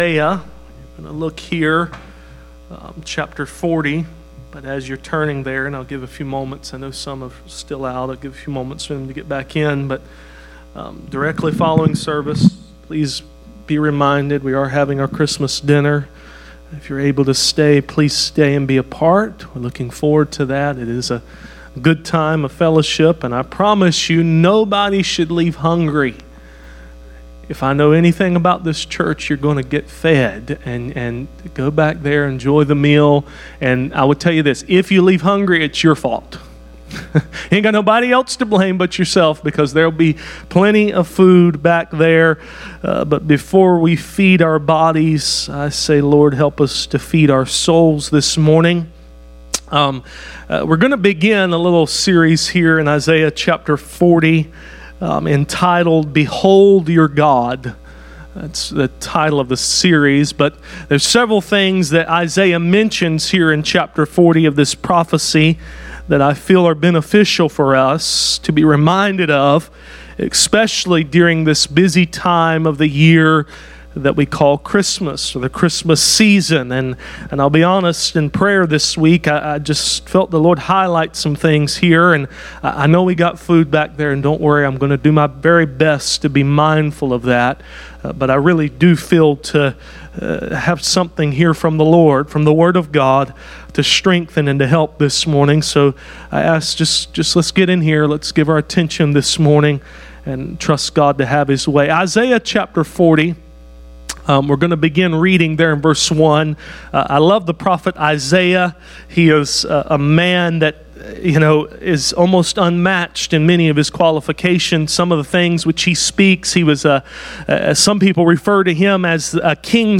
None Passage: Isaiah 40:1-11 Service Type: Sunday Morning « Who is this King of Glory